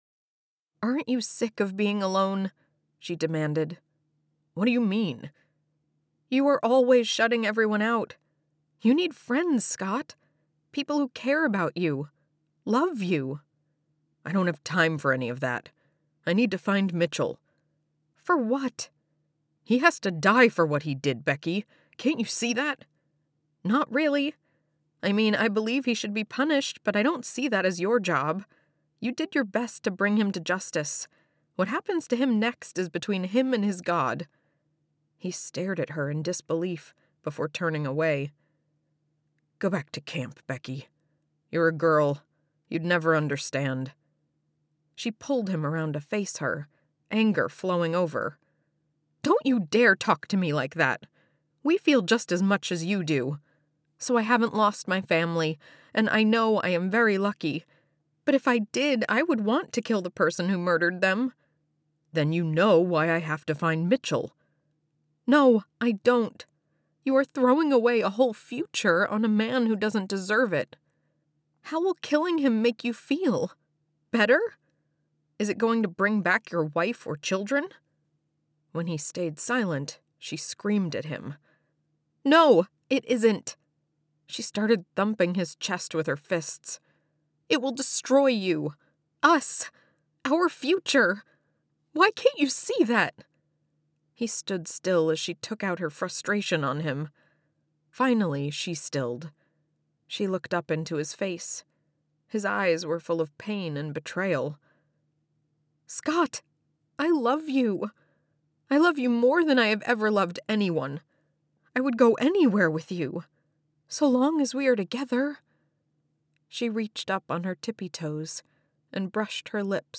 Wholesome Historical Romance - young adult female/adult male dialogue - 3rd person narration
Wholesome+Historical+Romance+-+male-female+dialogue.mp3